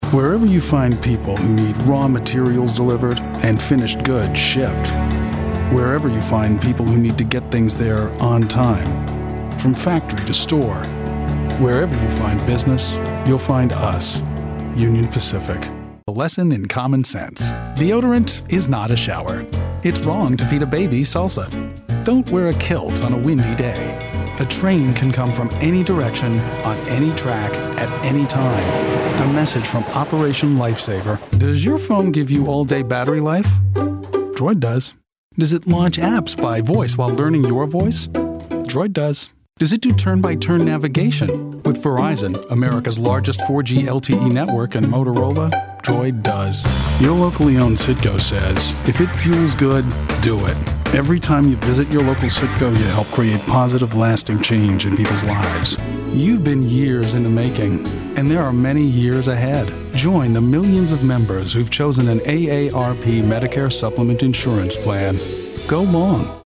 English - USA and Canada
Voice Age
Friendly, engaging, energetic, friendly and versatile.
Commercial